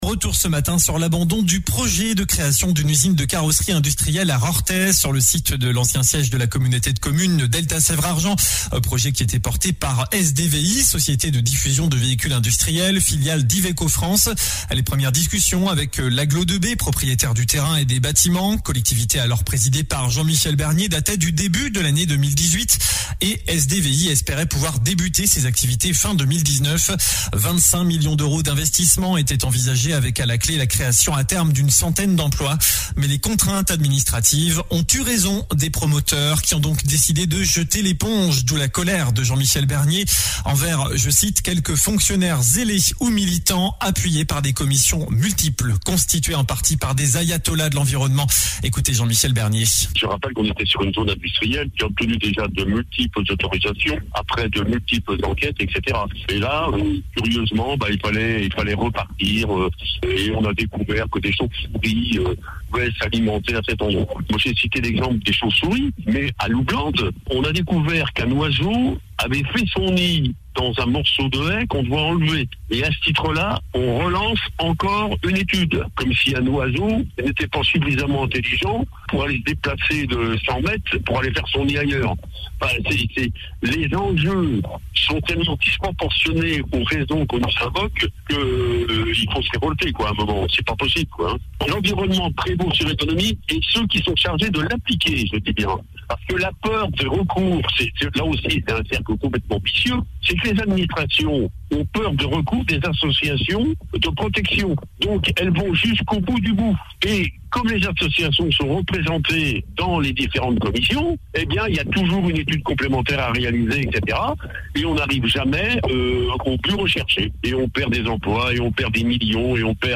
JOURNAL DU samedi 19 MARS